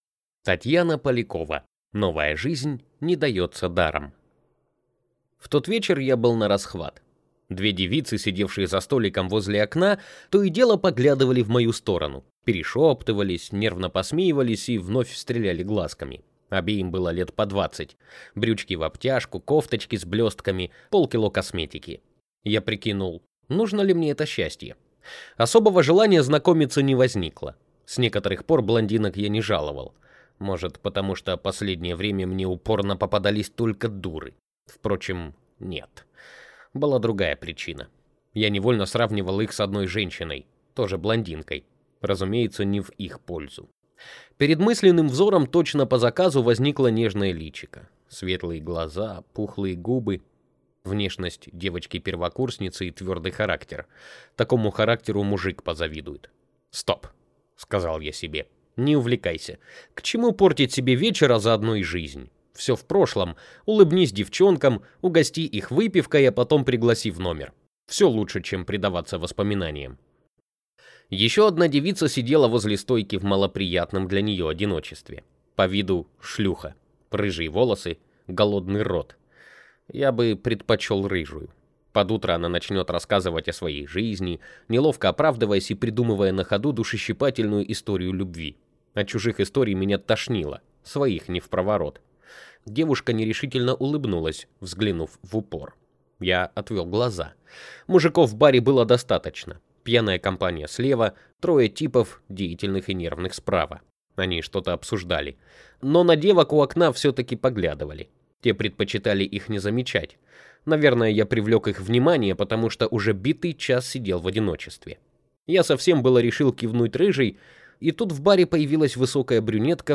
Аудиокнига Новая жизнь не дается даром (повесть) | Библиотека аудиокниг